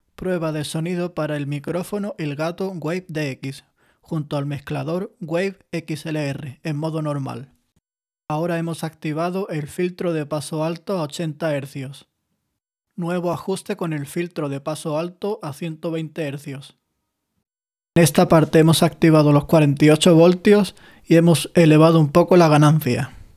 En el clip anterior hemos grabado nuestra voz con distintas configuraciones para ver si existen diferencias. En la última parte con el voltaje Phantom activado y la ganancia elevada casi al máximo podemos ver que la captura sigue siendo muy clara sin introducir ruido ambiental. La calidad y detalle es impresionante en todas las pruebas llevadas a cabo, consiguiendo una gran fidelidad sin distorsión.